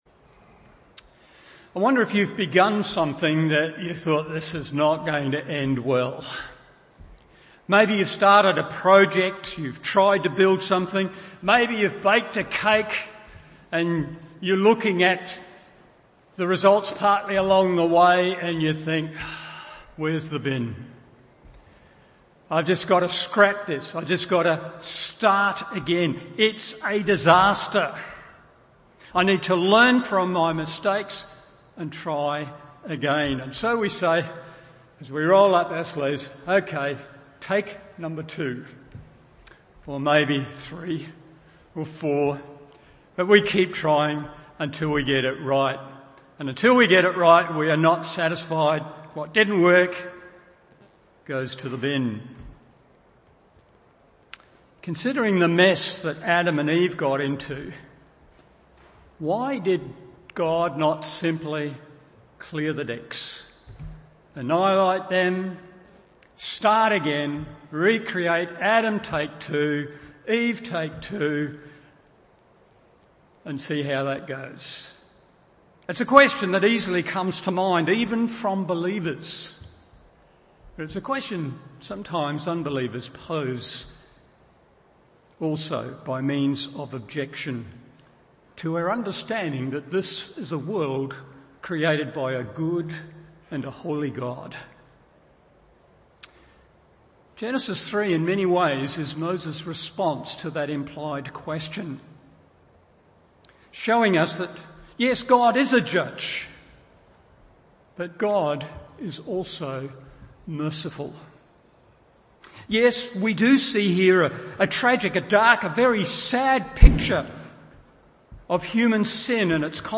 Morning Service Genesis 3:8-25 1.